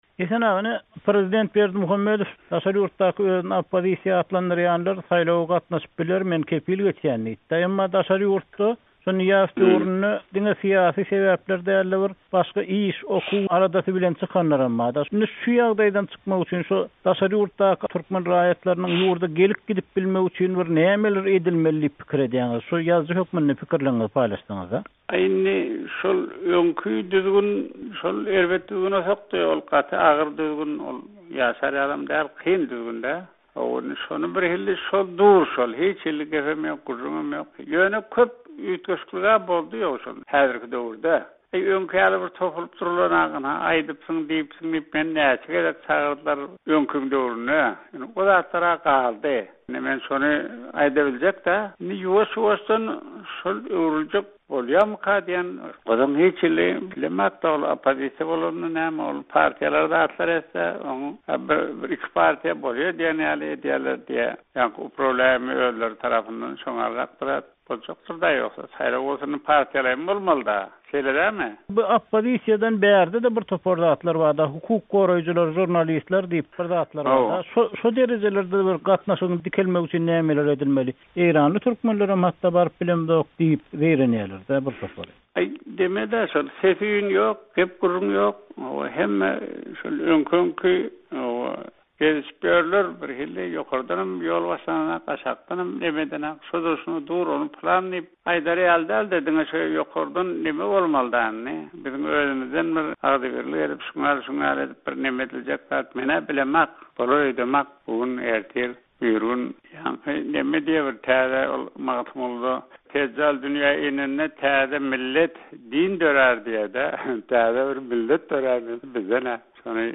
Azatlyk radiosy Türkmenistanda gadaganlykda saklanan ýazyjy bilen 4 ýyl öň geçiren söhbetdeşliginiň bir bölegini okyjylara ýetirmegi makul bildi.